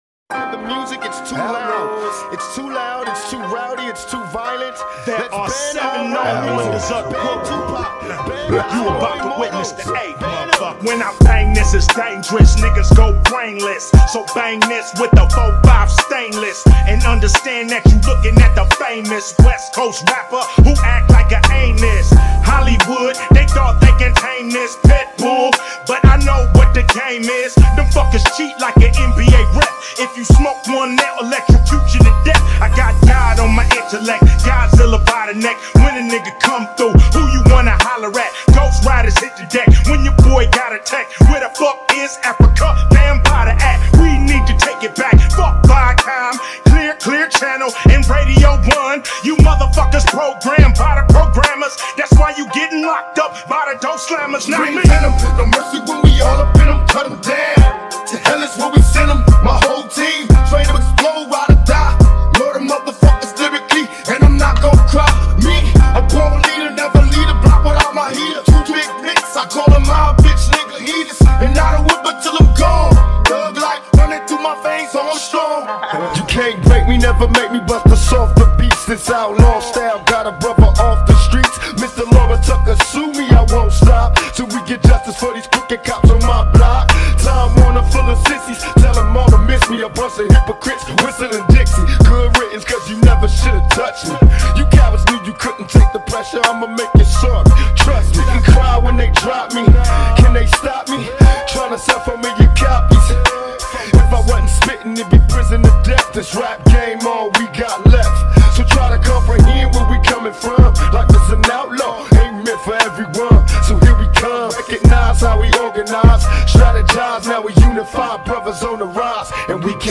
ژانر: ریمیکس
اهنگ باشگاهی خفن خارجی